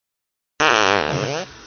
真实的屁 " 屁7
描述：真屁
Tag: 现实 放屁 真正